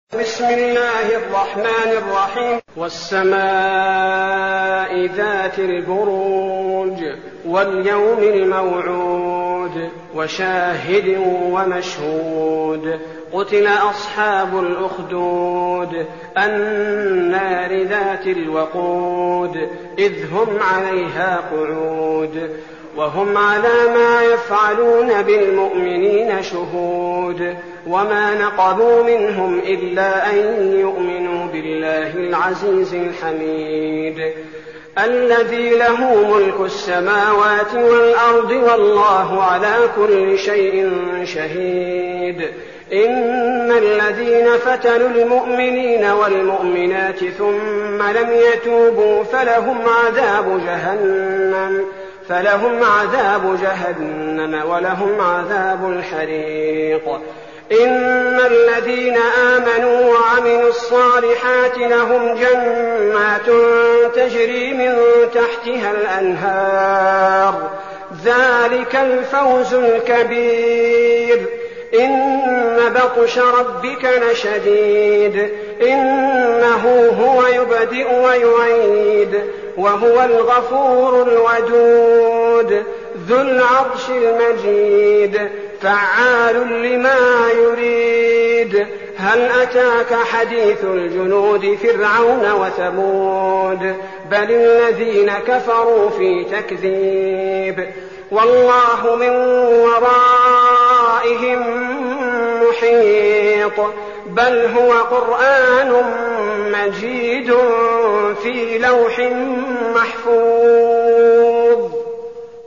المكان: المسجد النبوي الشيخ: فضيلة الشيخ عبدالباري الثبيتي فضيلة الشيخ عبدالباري الثبيتي البروج